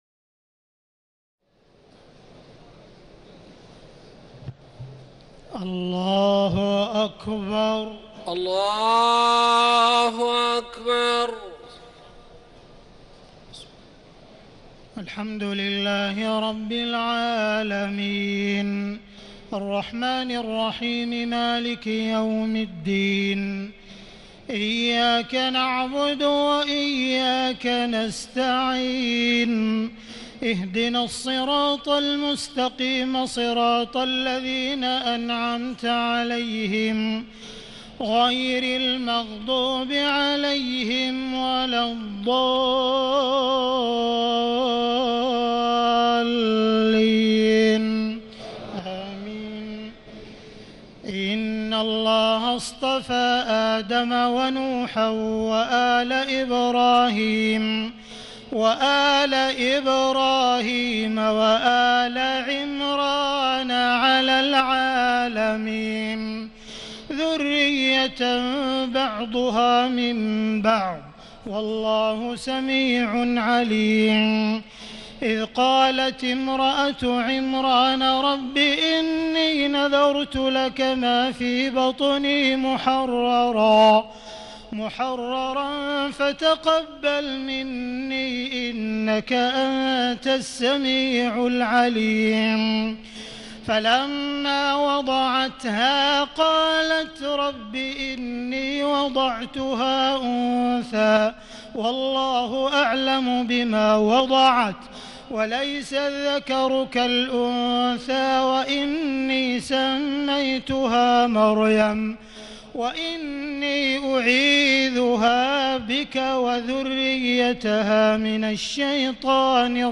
تهجد ليلة 23 رمضان 1439هـ من سورة آل عمران (33-92) Tahajjud 23 st night Ramadan 1439H from Surah Aal-i-Imraan > تراويح الحرم المكي عام 1439 🕋 > التراويح - تلاوات الحرمين